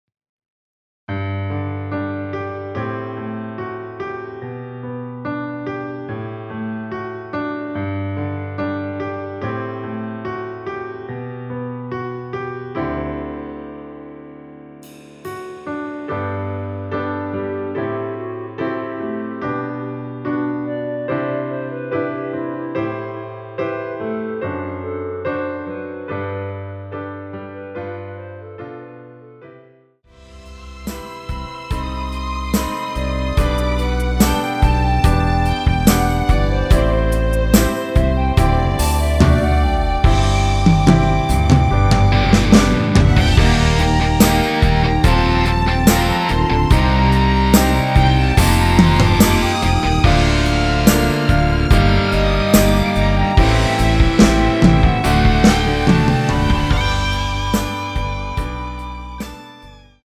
원키에서(+4)올린 멜로디 포함된 MR입니다.(미리듣기 확인)
앞부분30초, 뒷부분30초씩 편집해서 올려 드리고 있습니다.